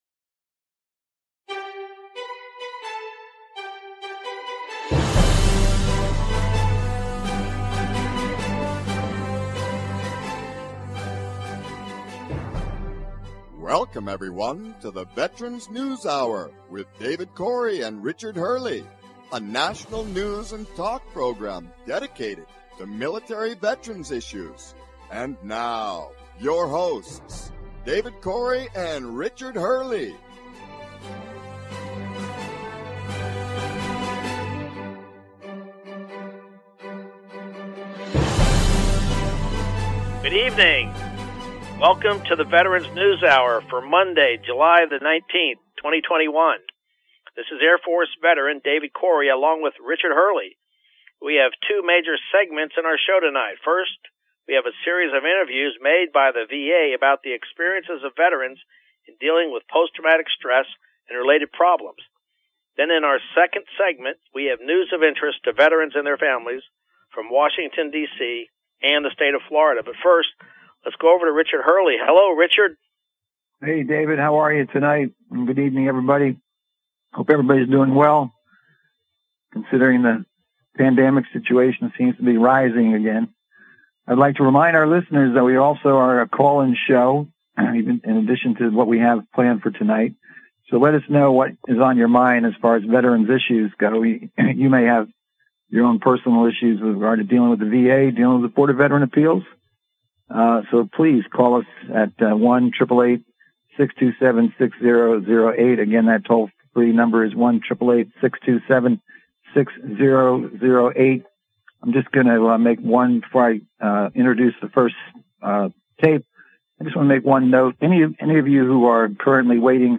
News and talk show about military veterans issues, including VA benefits and all related topics.